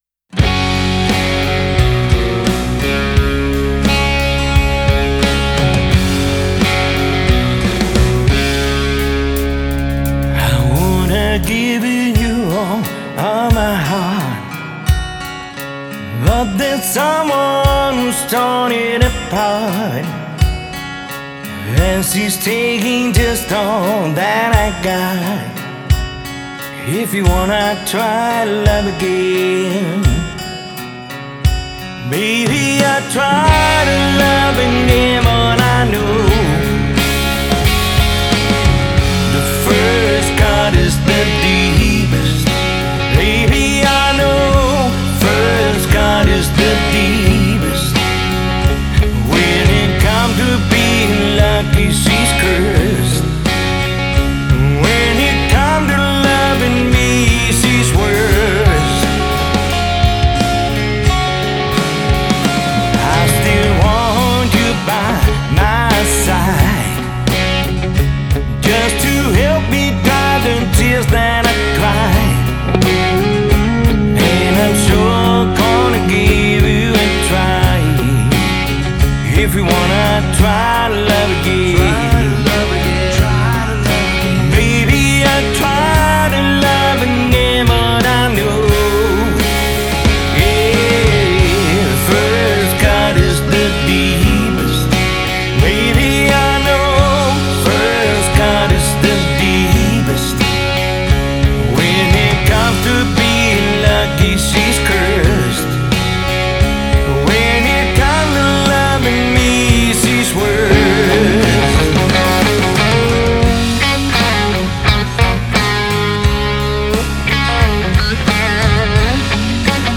• Coverband
• Dansband